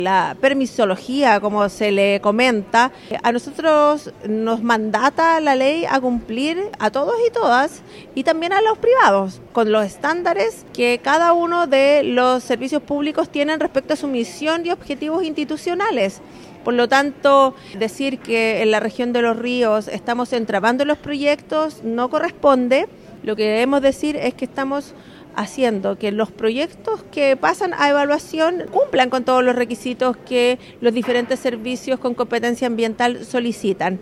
De los 8 proyectos que actualmente esperan o trabajan para conseguir su Resolución de Calificación Ambiental, el 80% son del ámbito energético. La seremi de Energía, Claudia Lopetegui, aseguró que por ley, los proponentes deben cumplir con la tramitación y los estándares exigidos.